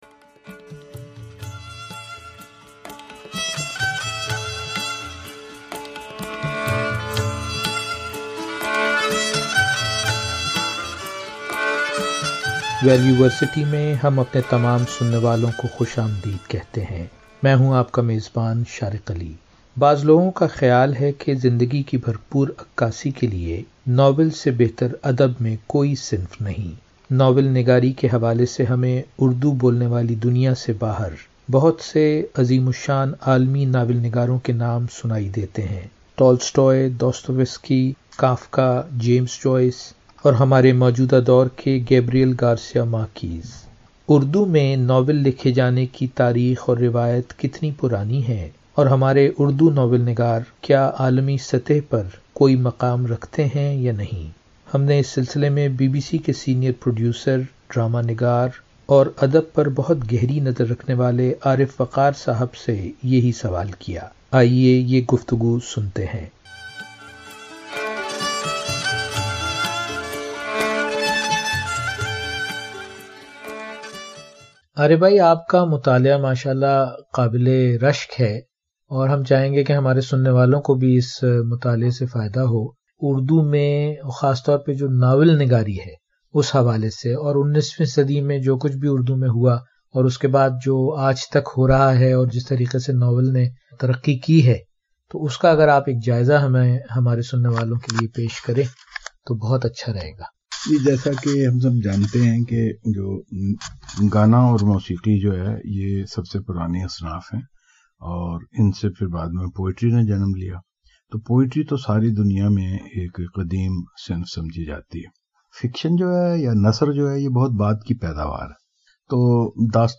Part 1 Fictional written narrative which can be regarded as novel is a relatively recent phenomenon in Urdu literature. Over a period of about 135 years, how Urdu novel evolved to its present day form is summarized in this brief conversation by BBC senior producer